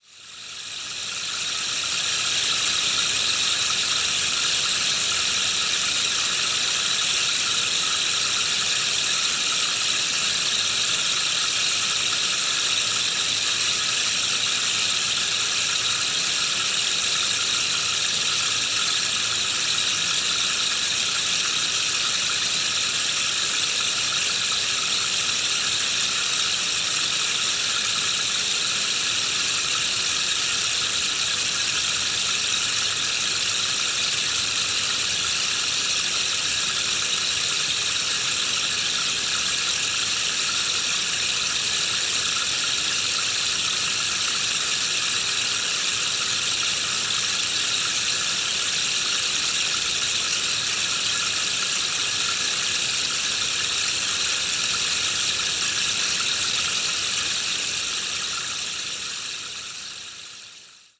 The Meinl Sonic Energy Rainmaker is crafted from high-quality, lacquered woods, producing a soothing and natural rain sound that is both calming and…
Its gentle, cascading tones make it the perfect sound backdrop for sound baths, sound journeys, yoga sessions, and meditation, allowing you to play other instruments simultaneously.